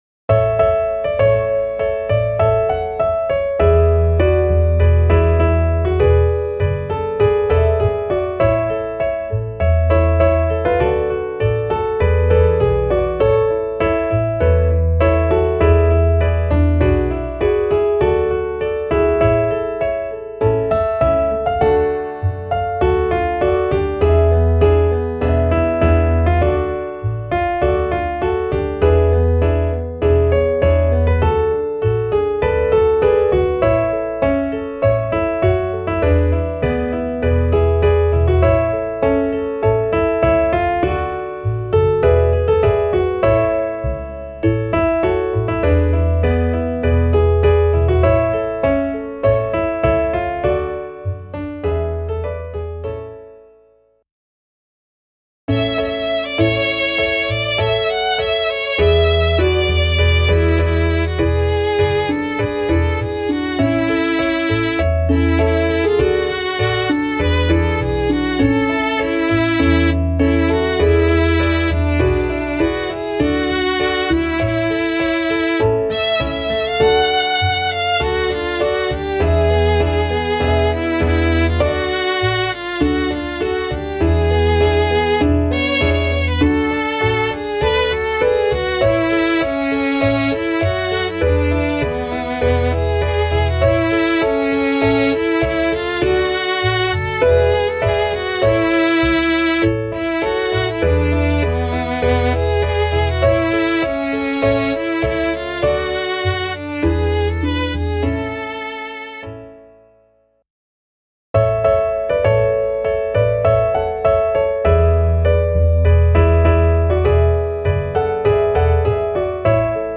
Klaviersatz